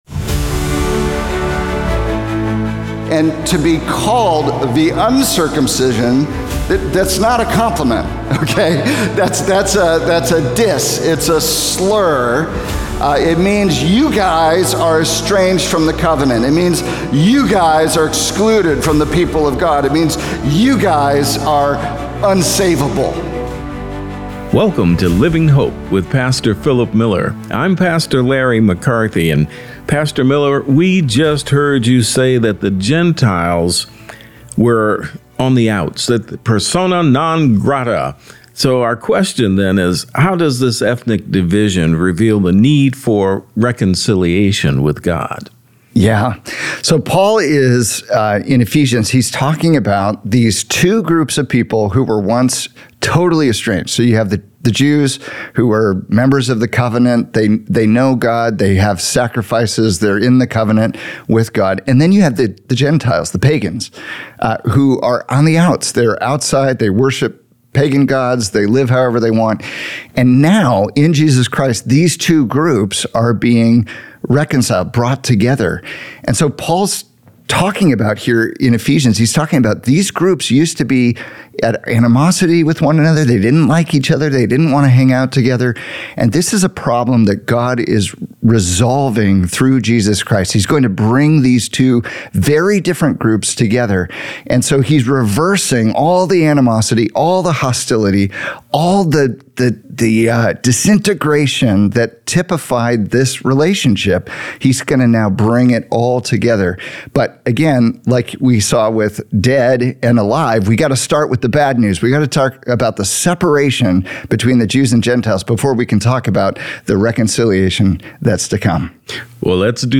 5 Deficiencies of a Life Alienated from God | Radio Programs | Living Hope | Moody Church Media